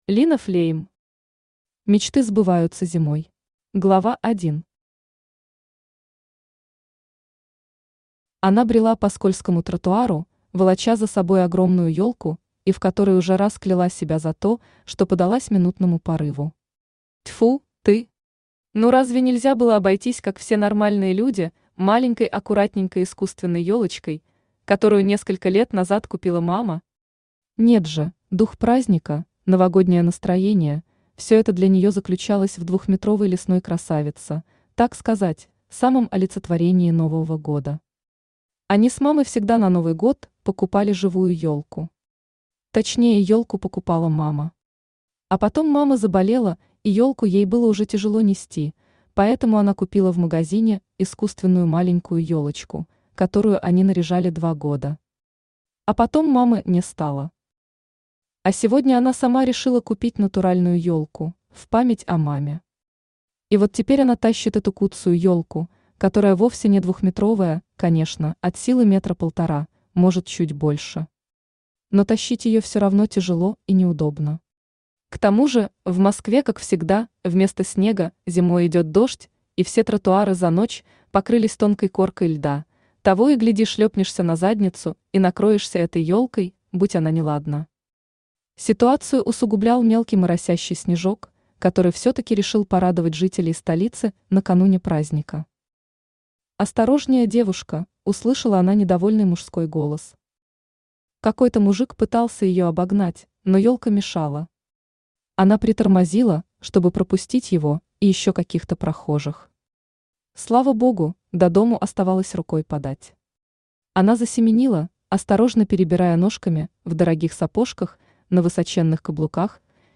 Aудиокнига Мечты сбываются зимой Автор Лина Флейм Читает аудиокнигу Авточтец ЛитРес.